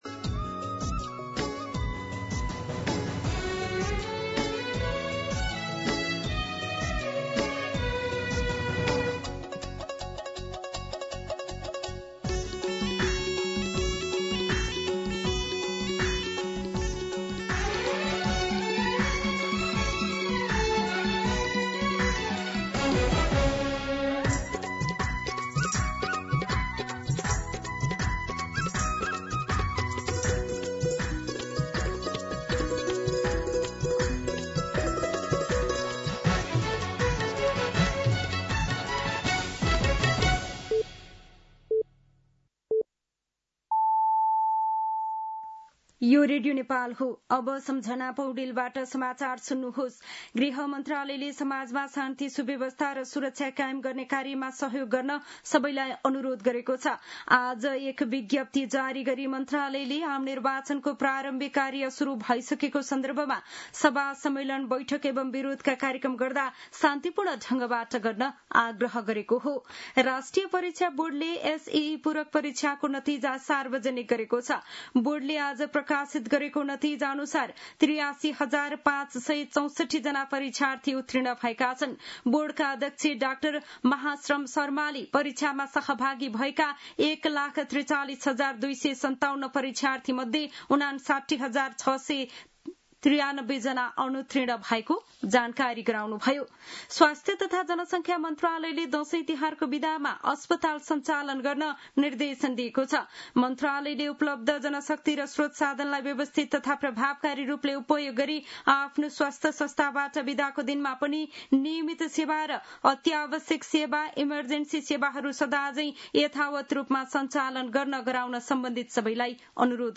दिउँसो ४ बजेको नेपाली समाचार : ११ असोज , २०८२
4-pm-Nepali-News-4.mp3